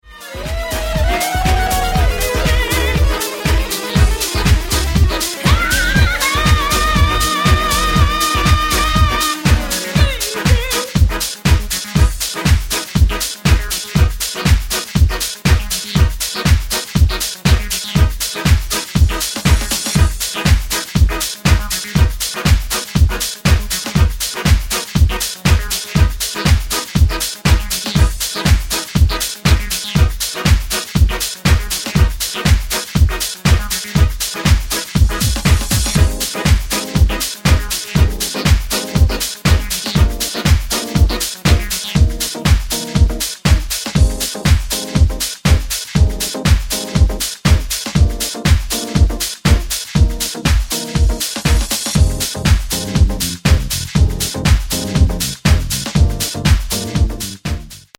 Take a look at how a typical newbie’s transition sounds.
At 00:20, the DJ begins to bring track B in to the dancefloor while taking out track A. At 00:36, B introduces itself with an instrumental intro and then completely takes over at 00:52.
But B’s 8-bar phrases are three bars ahead of A’s ones, and so B’s instrumental intro at 00:36 begins too early – three bars before A’s ending completes at 00:42.
phrase_matching-wrong_explained.mp3